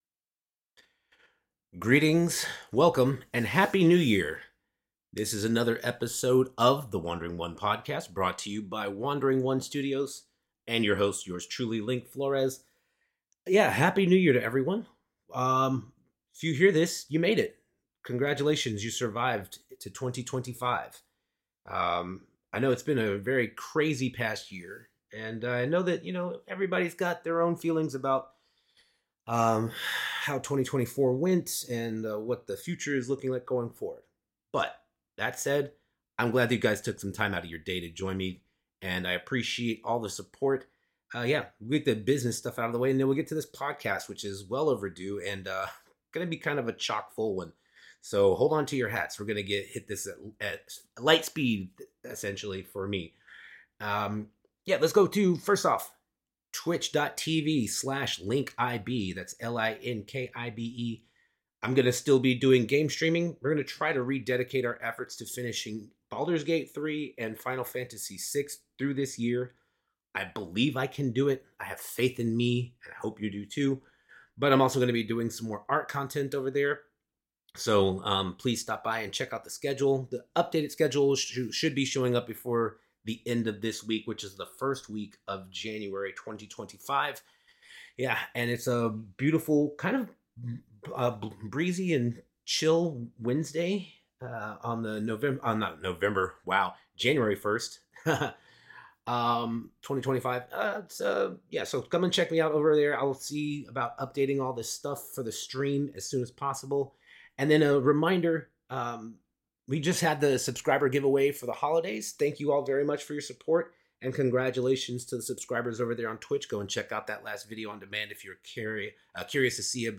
<<< WARNING: May contain adult language and thematic content. Listener discretion is advised. >>>